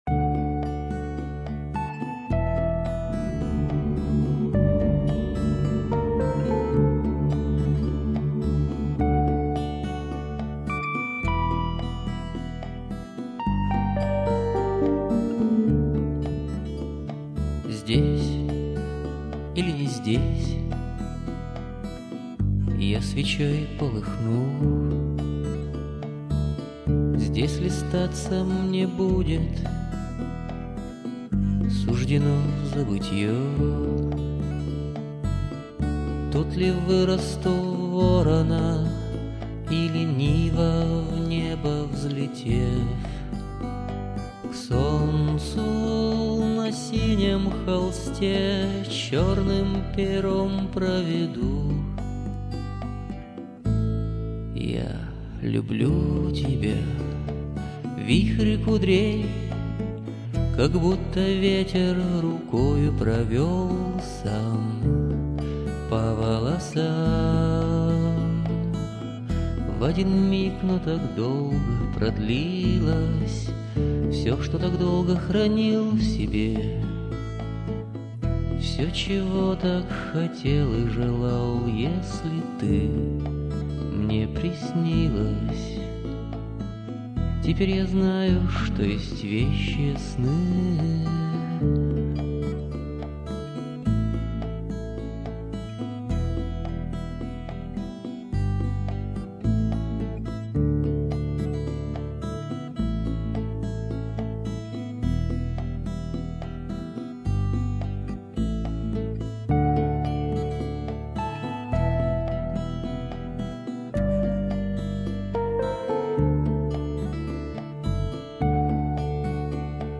Арт-рок и авангард.